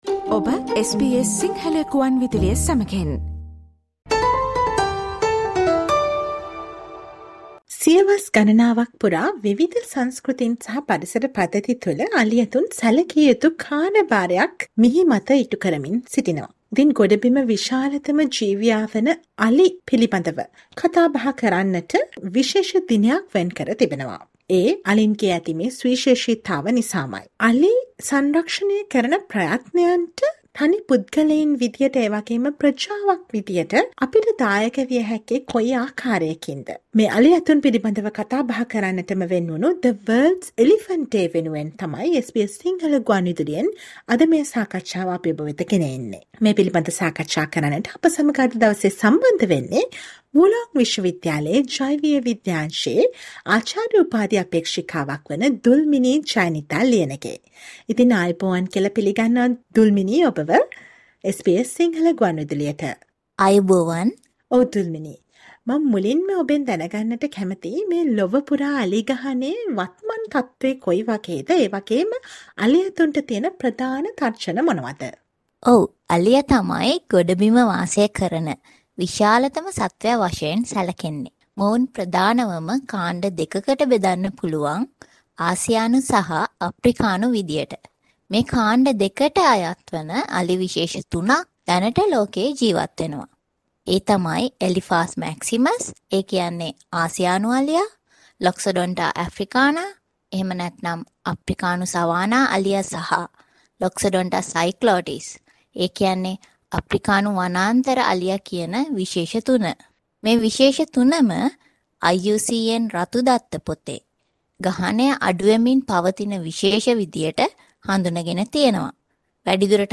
අලි ඇතුන් පිළිබඳව කතා බහ කරන්නටම වෙන්වුණ අගෝස්තු 12 වැනිදාට යෙදෙන The world elephants day වෙනුවෙන් SBS සිංහල සේවය සිදු කල සාකච්චාවට සවන් දෙන්න